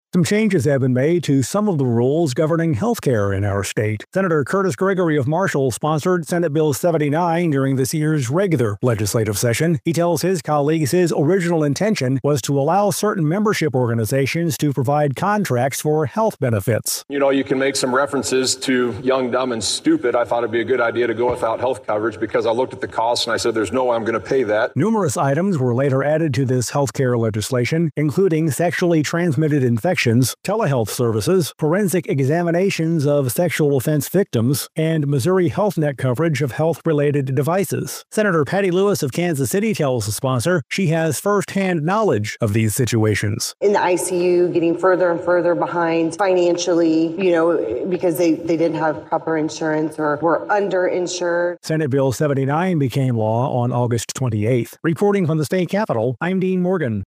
Senate Reporter